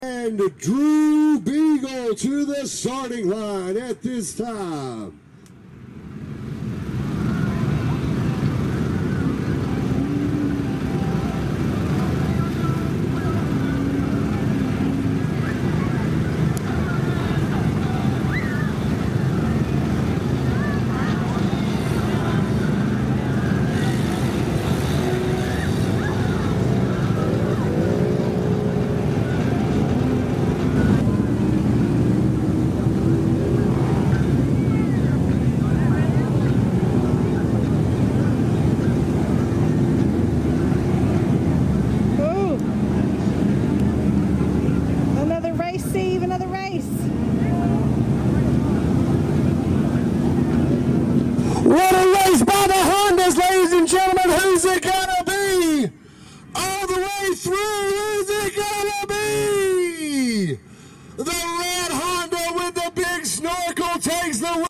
Honda vs Honda in the Busco Beach snorkel race across the ponds! It was a true race to the finish line!